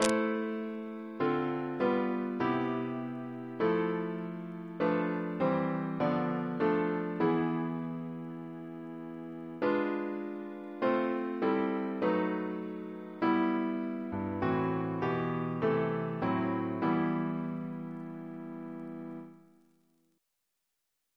Double chant in A minor Composer: George S. Talbot (1875-1918) Reference psalters: H1982: S9